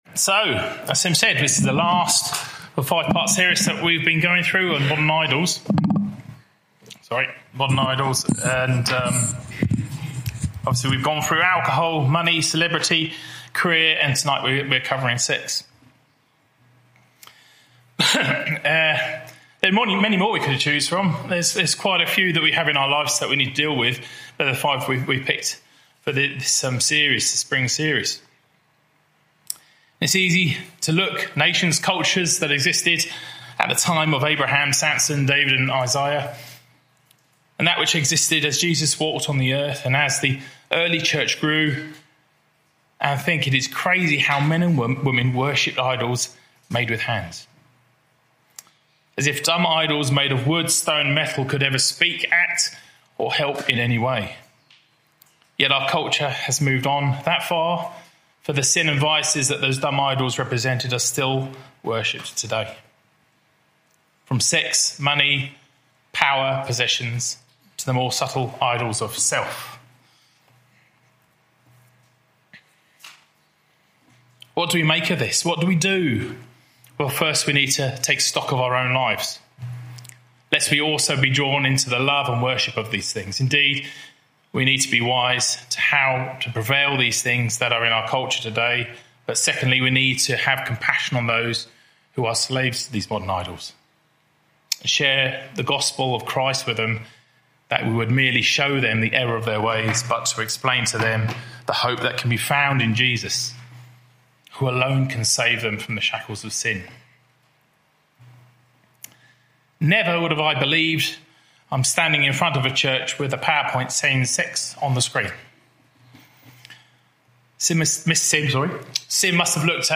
Topical Bible studies